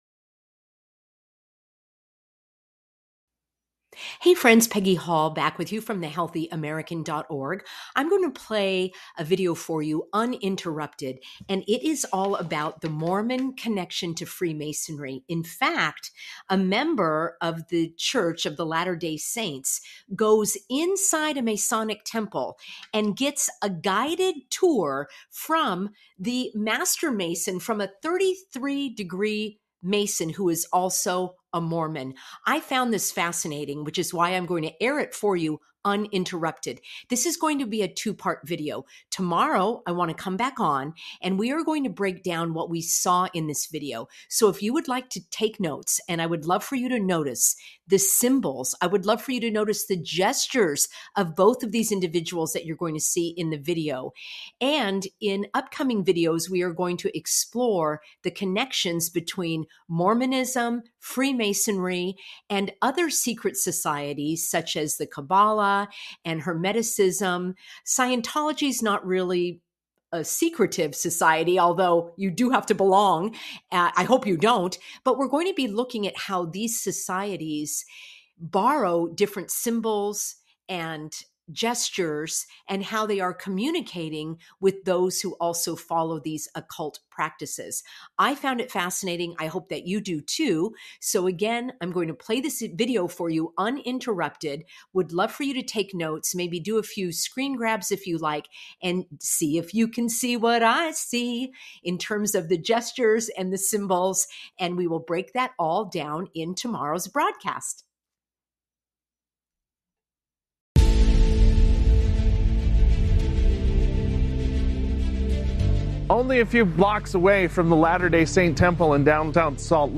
A Master Mason, who is also a Mormon, guides viewers through a Masonic Temple, revealing symbols and gestures. Discover how these societies connect and their influence on Joseph Smith, the founder of Mormonism.